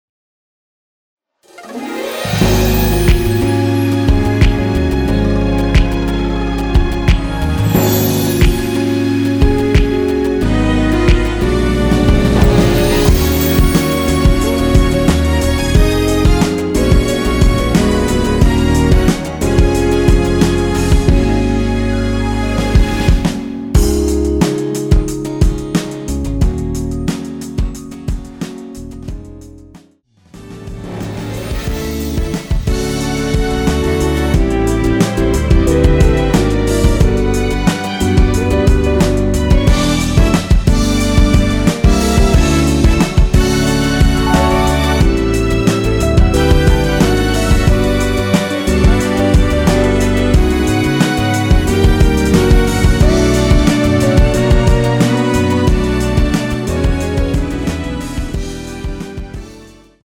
원키에서(-2)내린 MR입니다.
Db
앞부분30초, 뒷부분30초씩 편집해서 올려 드리고 있습니다.
중간에 음이 끈어지고 다시 나오는 이유는